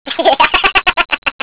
Quelques petit morceaux pour le smartphone : Un son de cr&eacuteature comme notification de message...